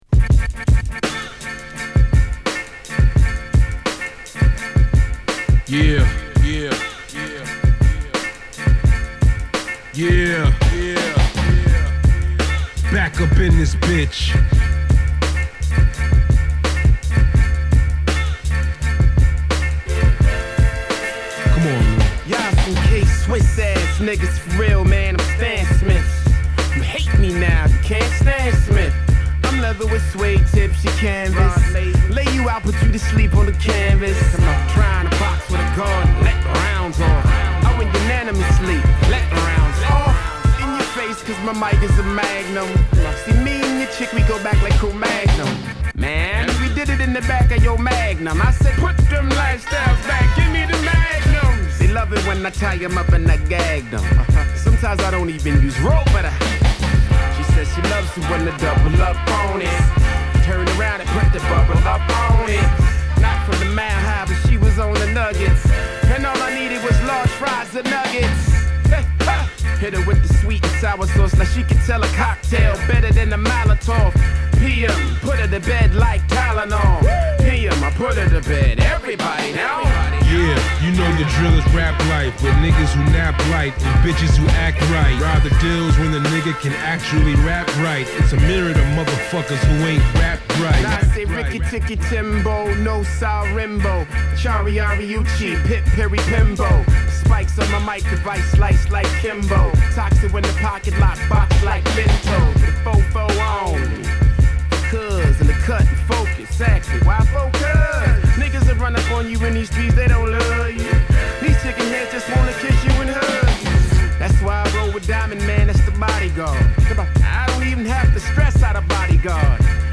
＊試聴はA1→A3→C3→D1です。